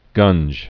(gŭnj) Chiefly British Slang